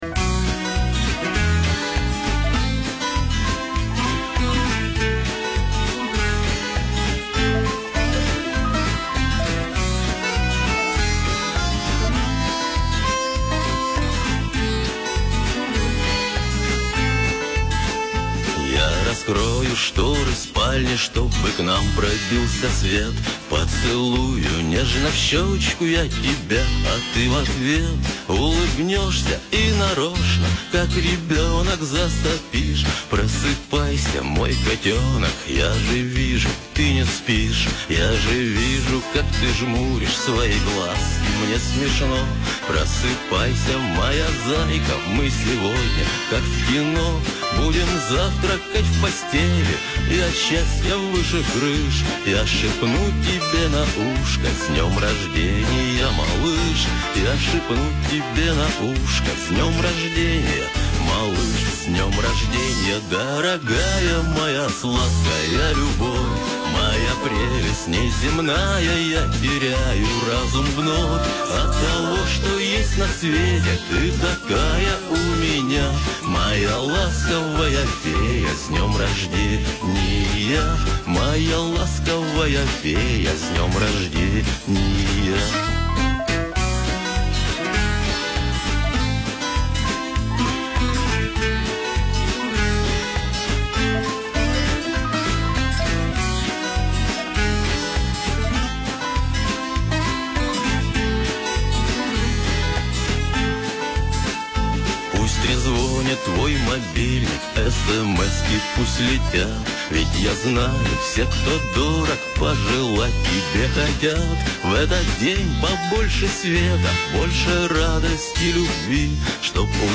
новая песня